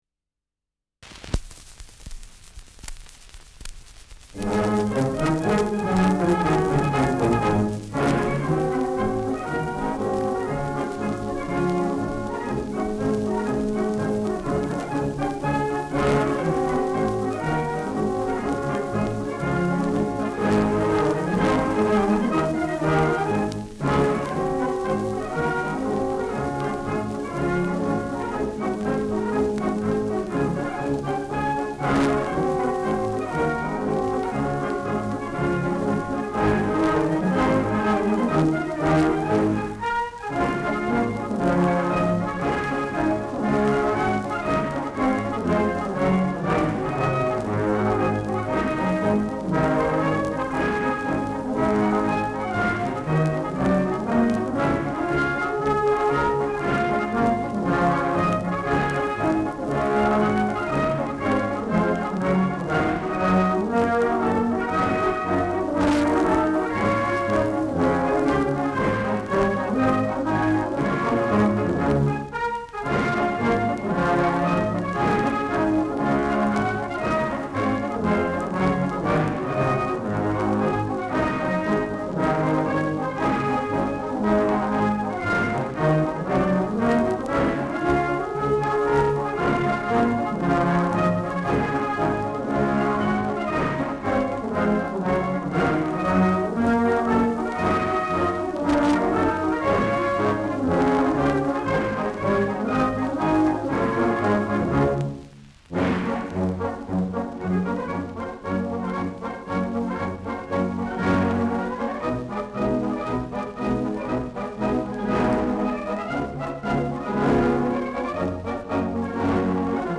Schellackplatte
Deutlich abgespielt : Dumpfer Klang : Verstärktes Grundrauschen : Knistern : Gelegentliches Knacken
[unbekanntes Ensemble] (Interpretation)
Etikett: Electric Recording : Imperial : Orchester : No. 2302 : In Treue fest : Marsch (C. Teike) : Blas-Orchester : 2242 BB